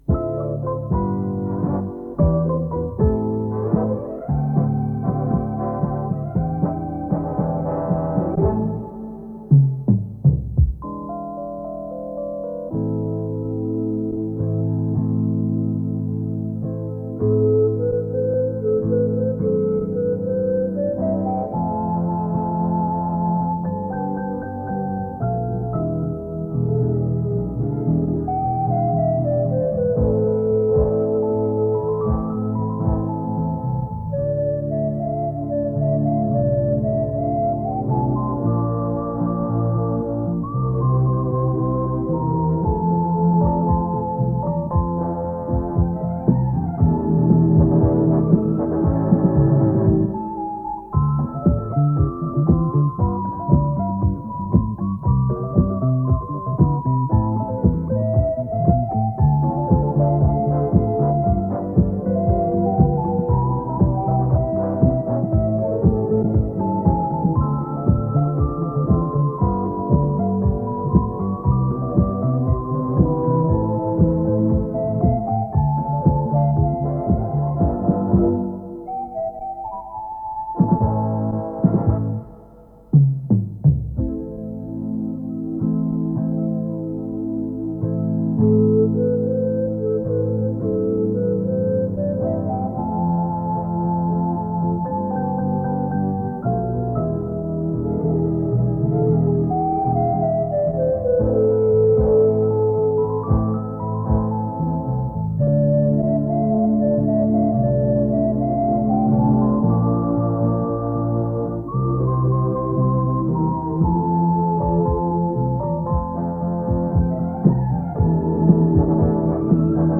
Всесоюзное радио. Март 1988 года (записи ровно 30 лет). Пара инструментальных пьес и выпуск новостей
Оцифровка бытовой катушки. Запись, к сожалению, не самого лучшего качества.
Очень неожиданно!Как необычна для современного уха спокойная речь ведущего!
Но вообще, конечно, запись весьма низкого качества, что не даёт возможности толком услышать интонации переходов в разговорной речи при чтении коротких (и длинных) читаемых предложений. cry